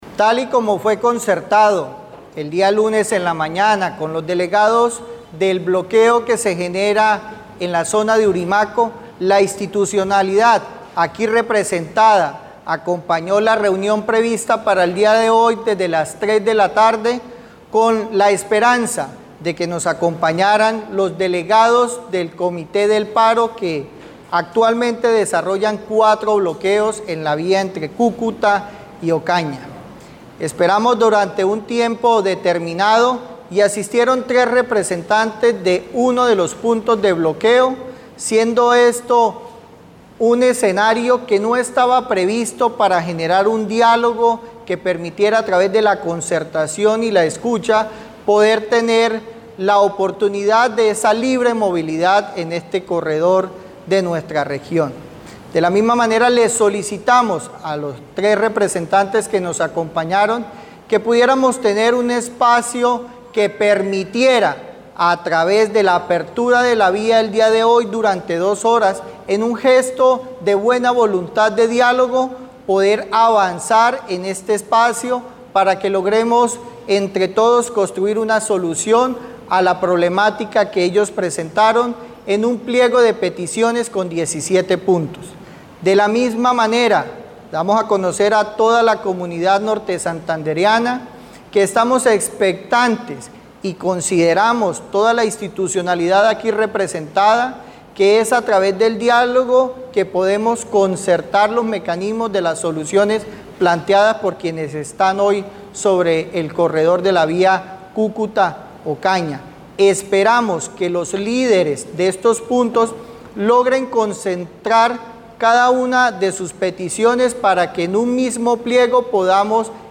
Audio-de-Olger-Lopez-secretario-de-Desarrollo-Economico.mp3